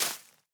Minecraft Version Minecraft Version latest Latest Release | Latest Snapshot latest / assets / minecraft / sounds / block / cherry_leaves / break3.ogg Compare With Compare With Latest Release | Latest Snapshot